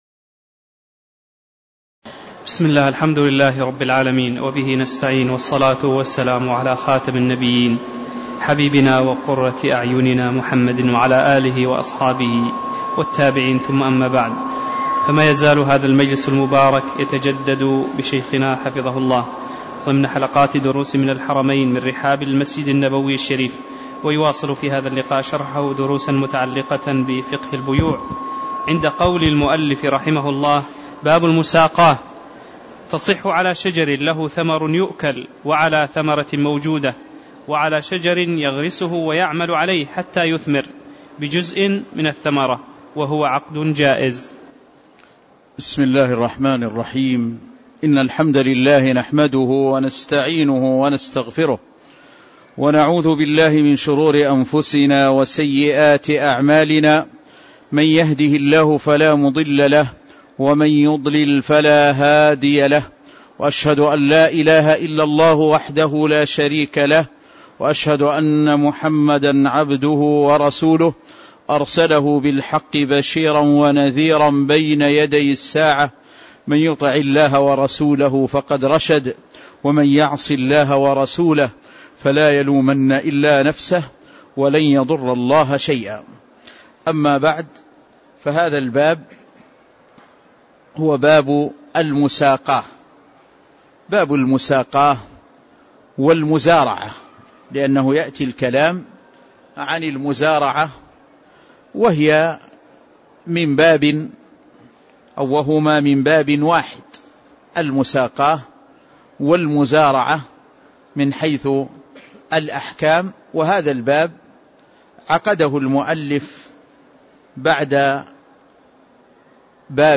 تاريخ النشر ٣ صفر ١٤٣٧ هـ المكان: المسجد النبوي الشيخ